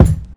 CDK - HB Kick.wav